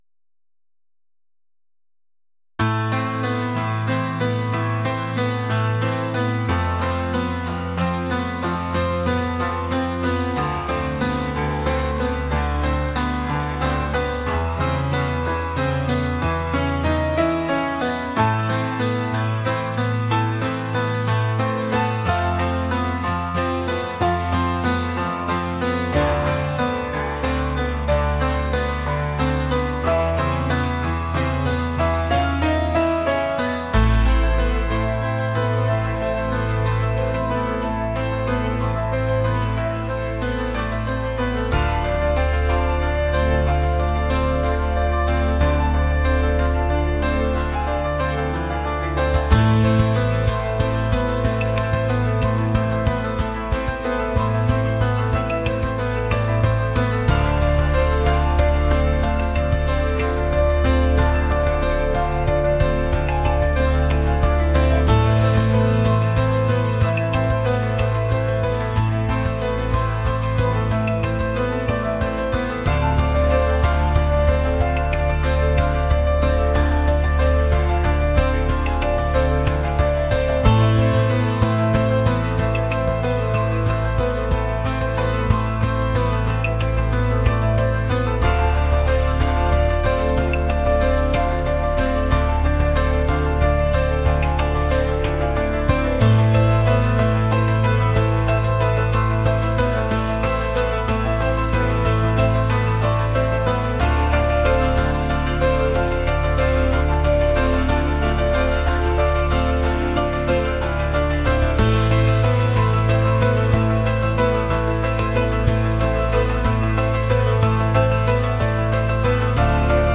シリアス